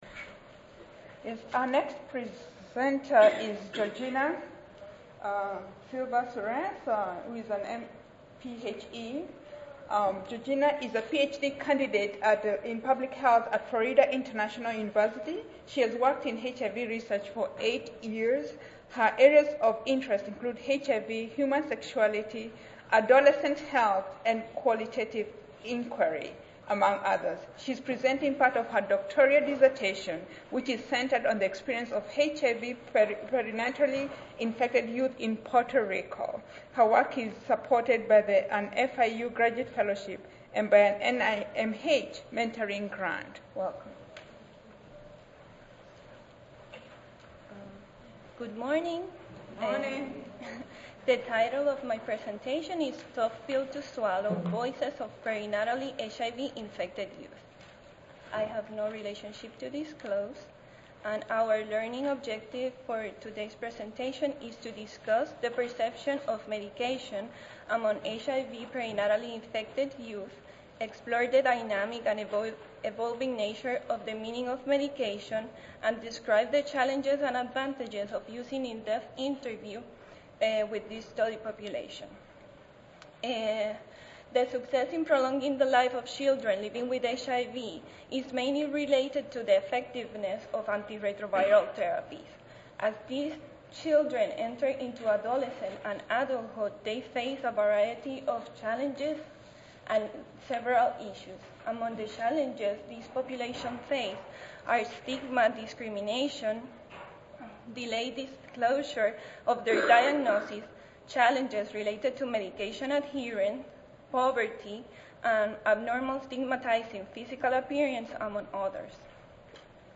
141st APHA Annual Meeting and Exposition (November 2 - November 6, 2013): Innovative approaches to HIV treatment: Successes and challenges